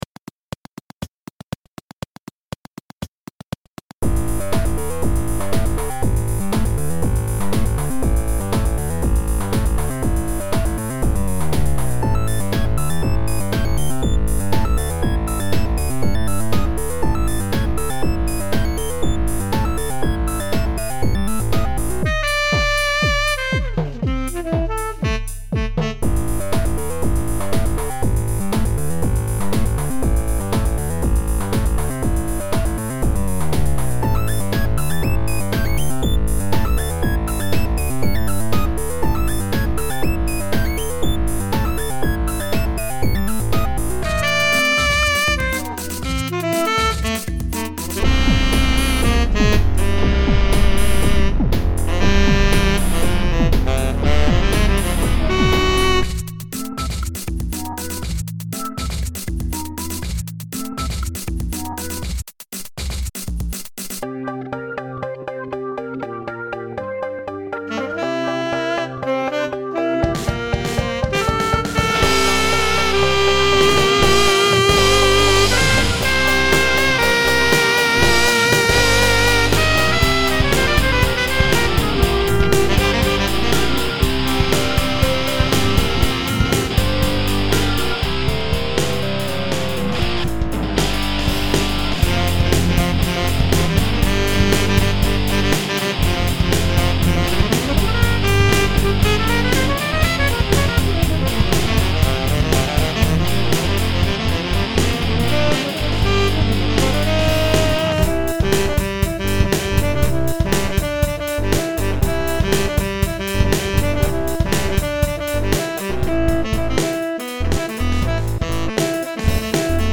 This song was my first attempt to sequence a sax part but I like the result and I might use it again.